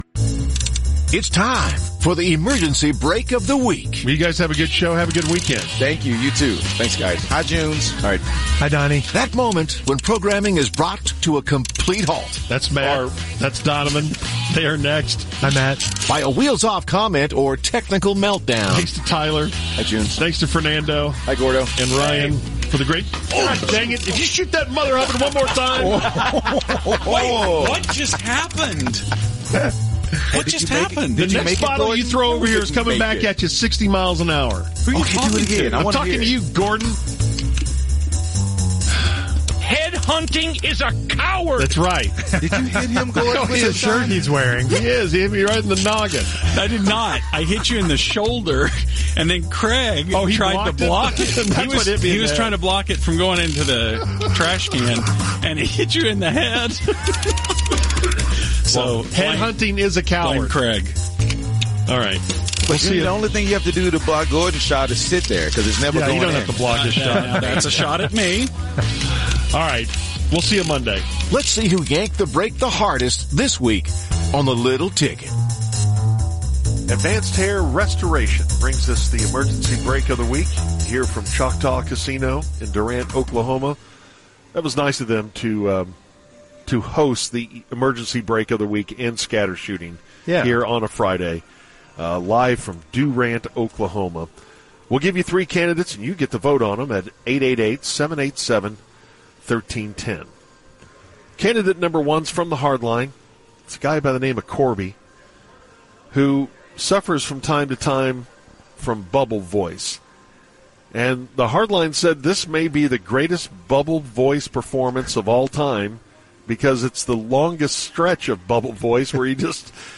Bubble voice